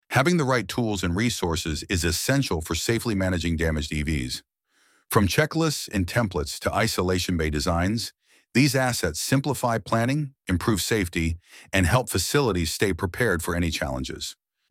ElevenLabs_Topic_3.1.3.mp3